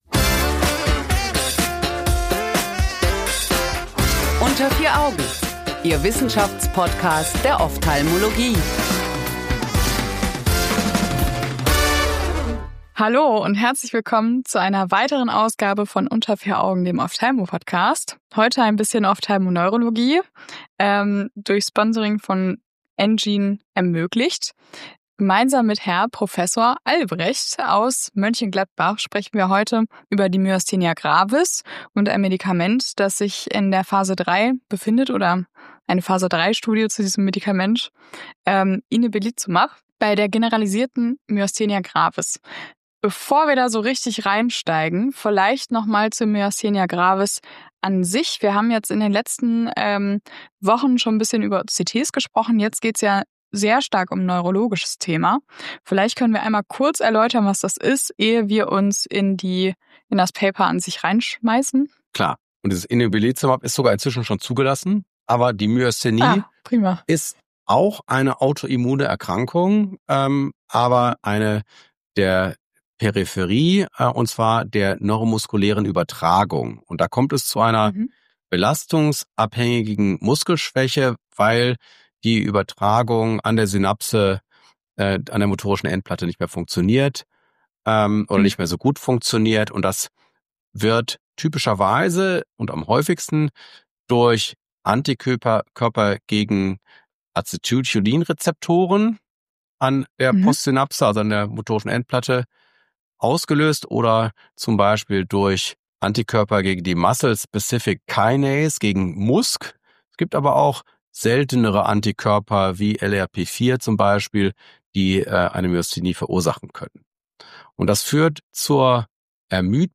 Inebilizumab bei Myasthenia gravis ~ Unter 4 Augen - der Wissenschaftspodcast der Ophthalmologie Podcast